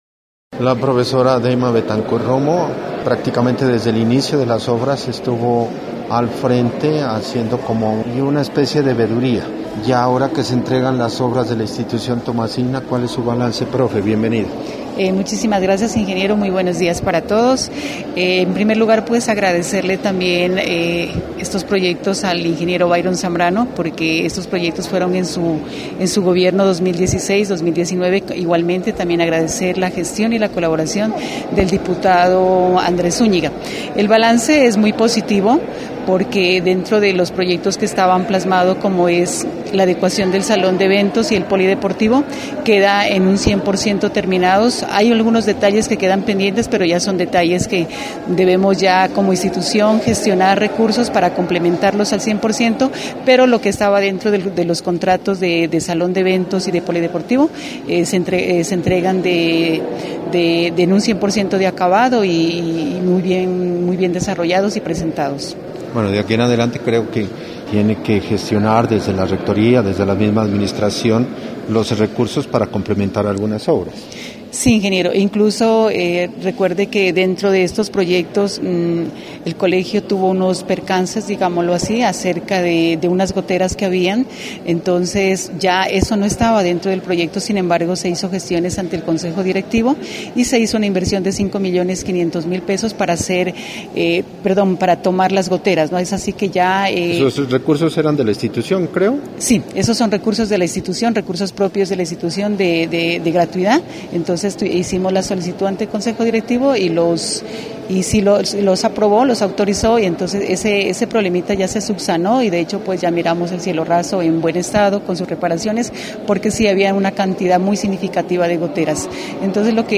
Los ingenieros responsables del proyecto de adecuación del salón de actos y construcción de la cubierta del polideportivo de la Institución educativa Tomás de Aquino de Sandoná hicieron entrega de las obras este viernes en horas de la mañana.